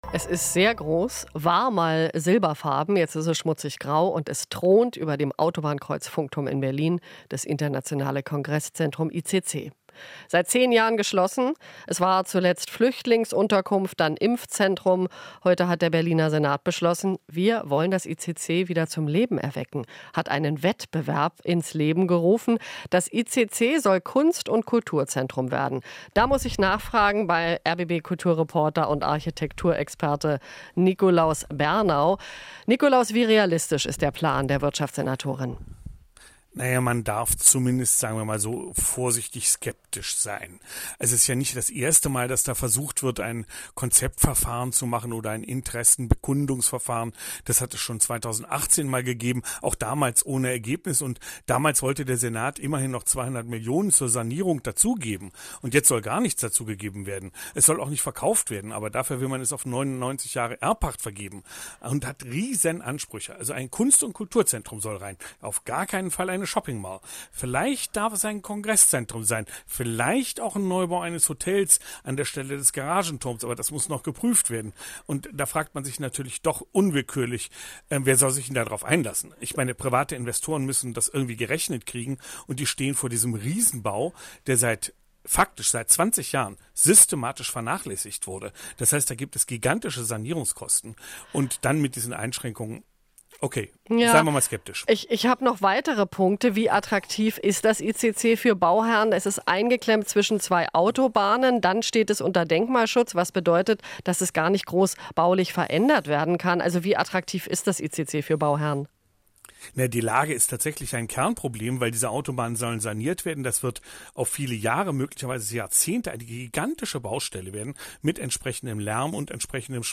Interview - Neue Pläne für das ICC vorgestellt